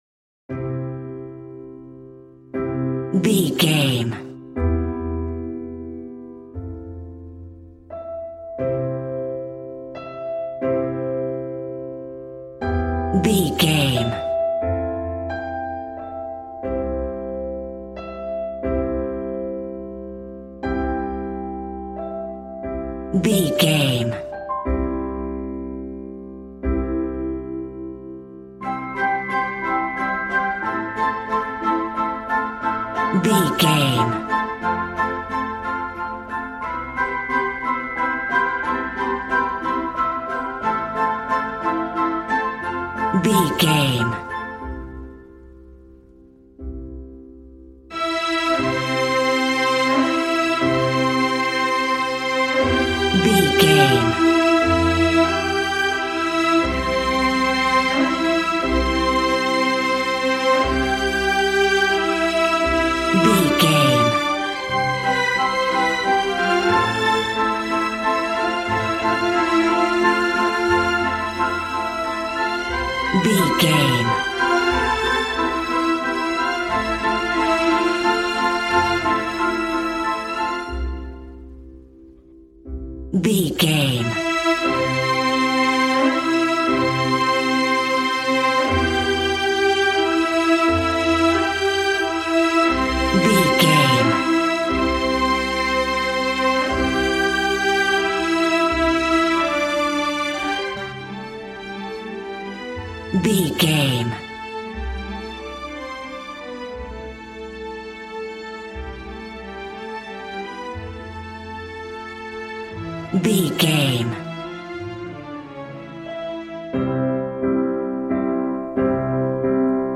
Ionian/Major
regal
strings
violin
brass